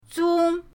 zong1.mp3